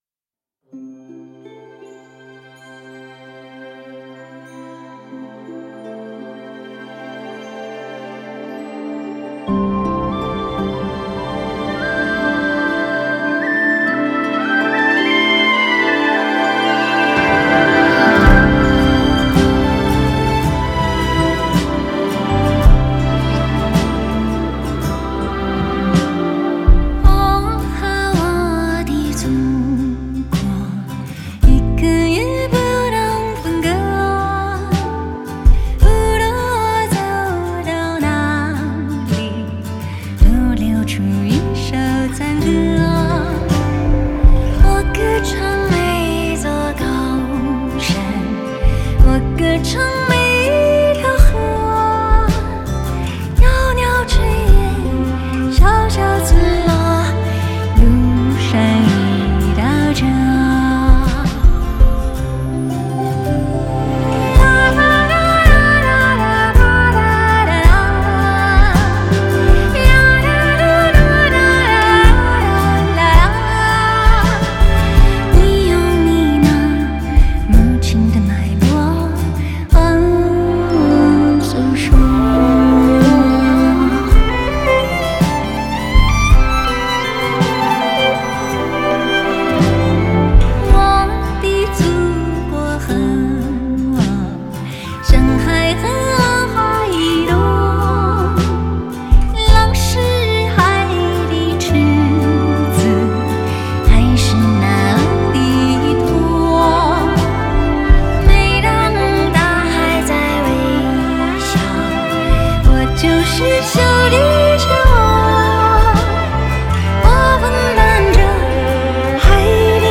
唱片风格：流行、怀旧